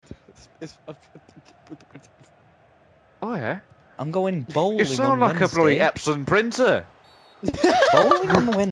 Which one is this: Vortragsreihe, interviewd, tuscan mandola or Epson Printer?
Epson Printer